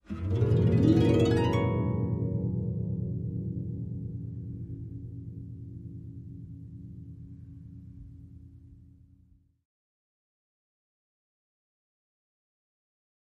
Harp, Low Strings Ascending Gliss, Type 2